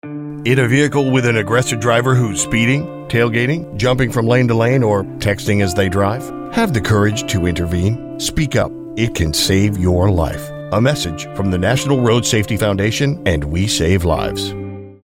National Passenger Safety Campaign Overview PSA